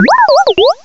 Add all new cries
cry_not_bounsweet.aif